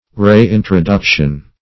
re-introduction - definition of re-introduction - synonyms, pronunciation, spelling from Free Dictionary